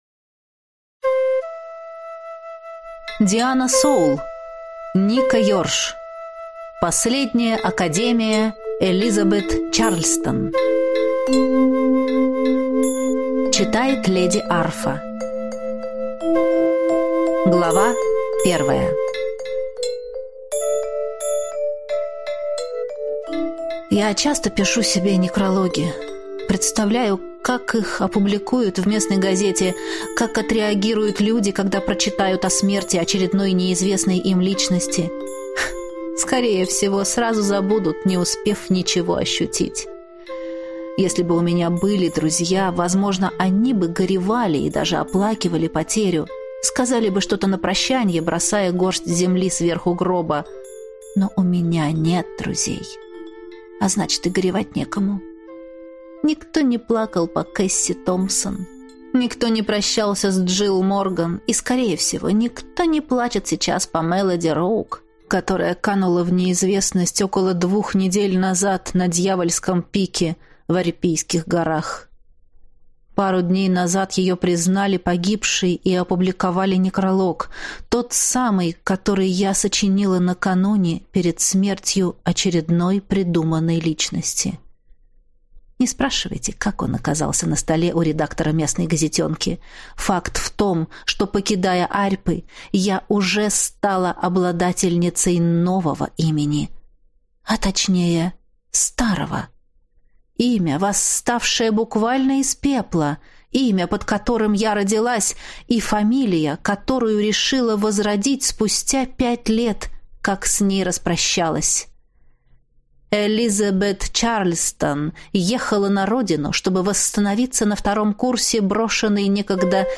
Аудиокнига Последняя Академия Элизабет Чарльстон | Библиотека аудиокниг